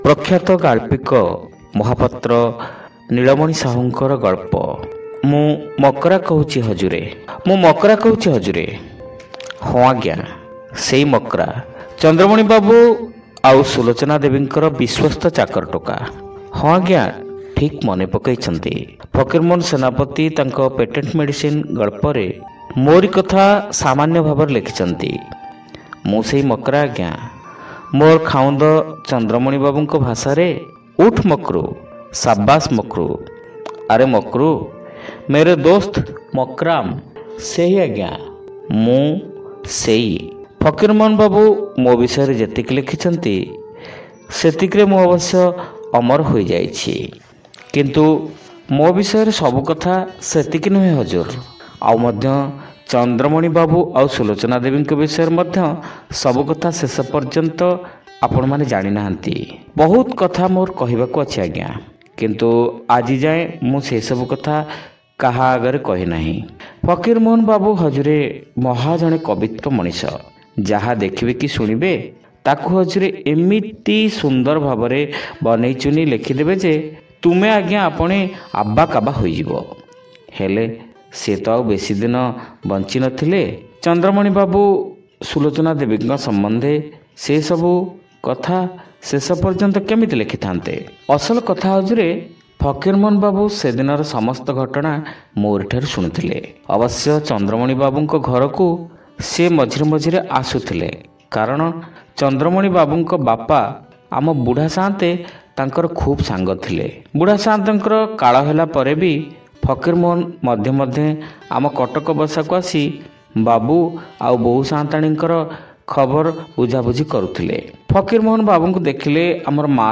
Audio Story : Mu Makara Kahuchhi Hajure (Part-1)